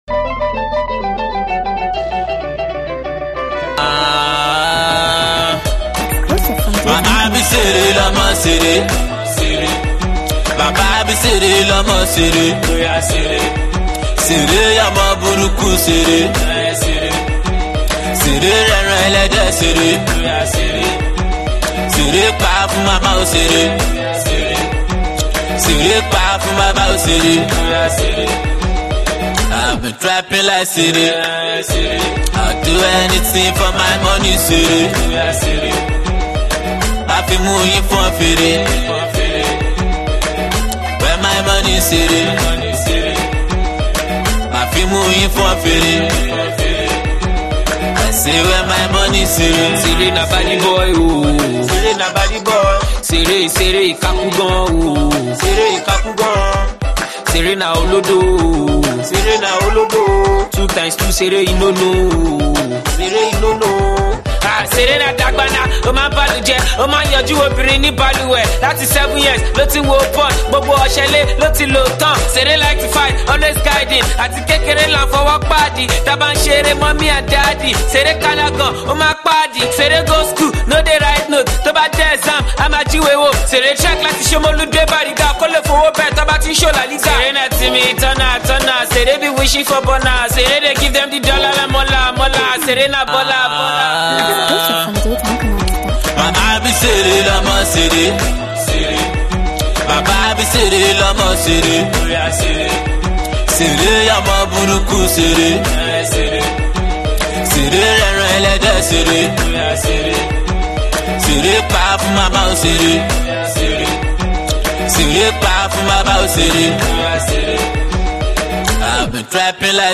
a new infectious sound